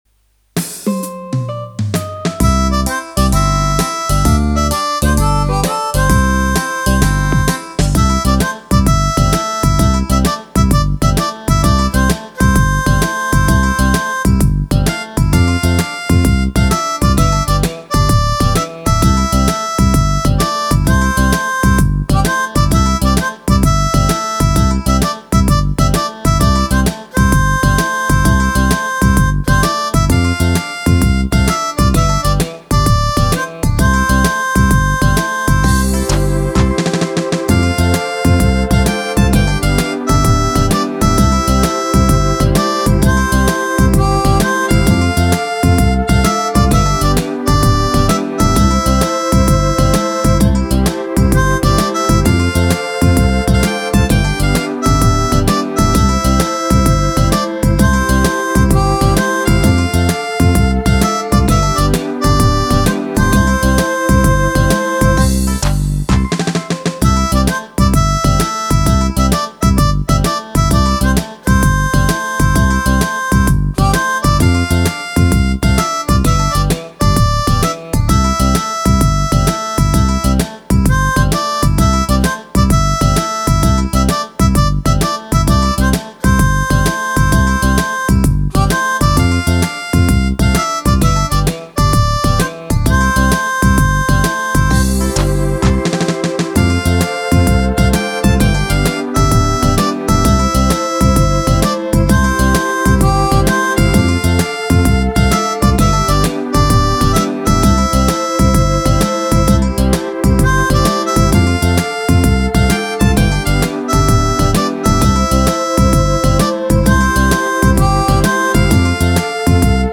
Country
Voor deze demo trok ik de kaart van de Country-categorie op de Roland E-X10, met het ritme 128 Country 1 aan 130 BPM. Het is een ritme met een uitgesproken, speels karakter dat meteen een heel eigen sfeer neerzet.
Met wat creativiteit ontstond er een licht, vrolijk deuntje met een echte feel-good countryvibe.
Roland E X 10 Country 128 Country 1 Mp 3